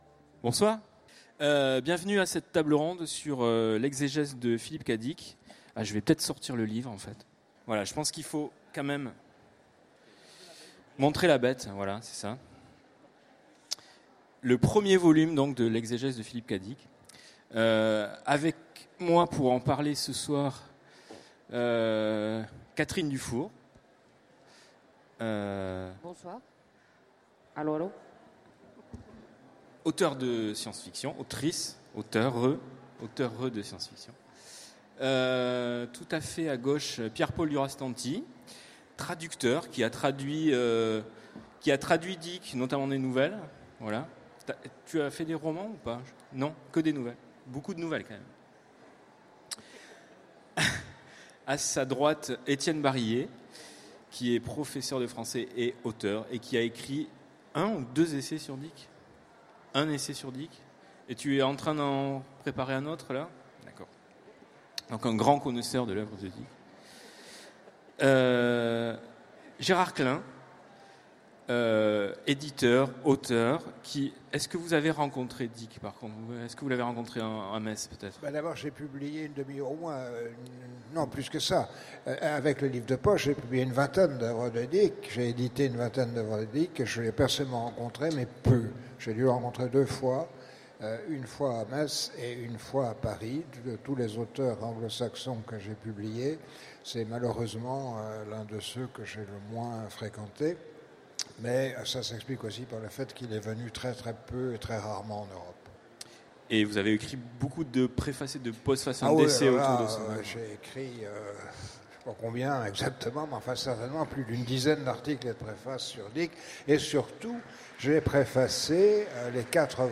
Utopiales 2016 : Conférence Philip K. Dick, L’Éxégèse